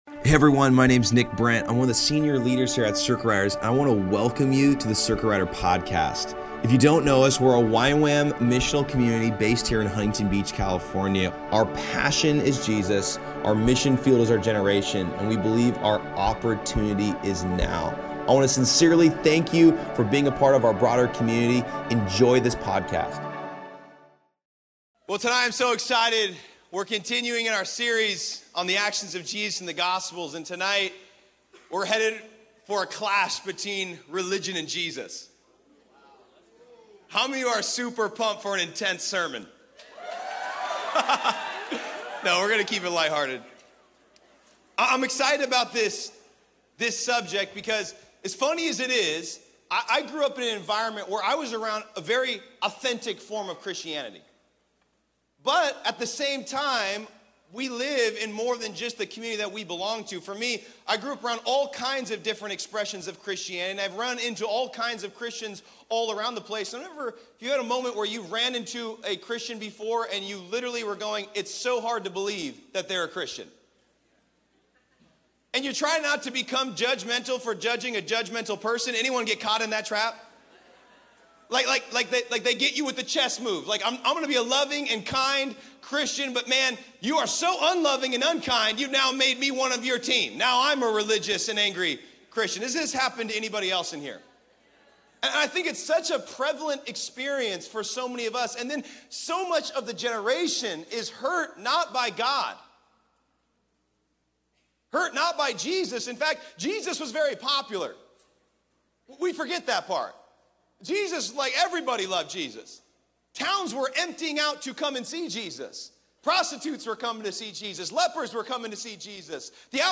At Circuit Riders Monday Nights.